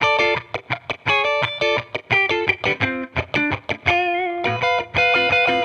Index of /musicradar/sampled-funk-soul-samples/85bpm/Guitar
SSF_TeleGuitarProc2_85D.wav